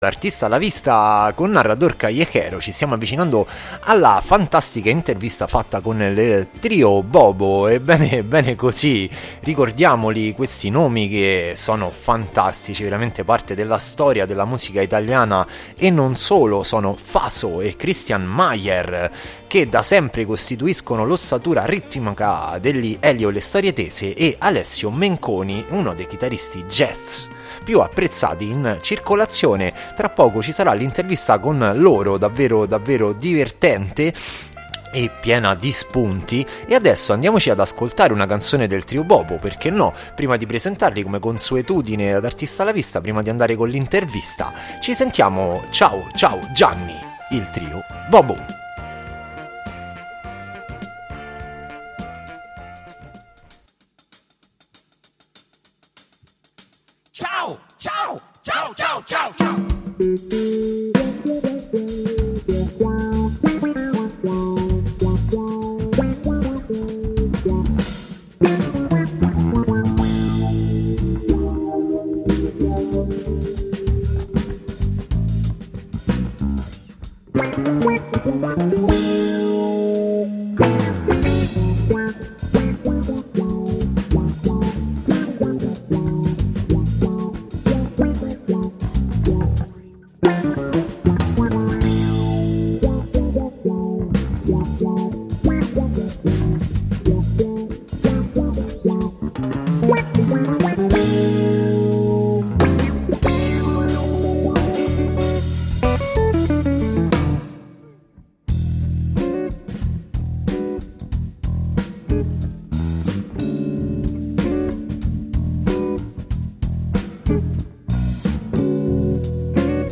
ARTISTA A LA VISTA | INTERVISTA IL TRIO BOBO | IL JAZZ E IL PROGRESSIVE ROCK | Radio Città Aperta
Artista-a-la-Vista-Intervista-il-Trio-Bobo.mp3